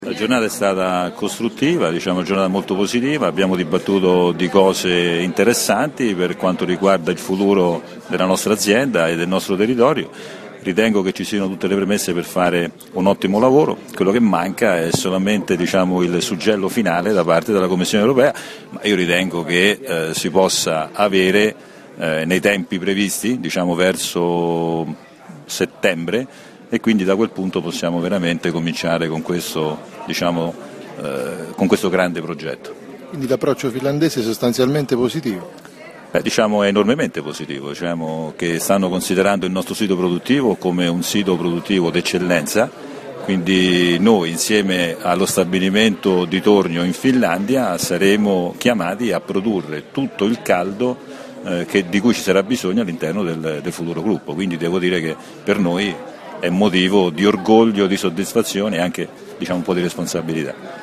INTERVISTA AUDIO